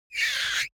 rabbit_injured.wav